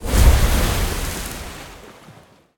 Sfx_creature_glowwhale_breach_out_01.ogg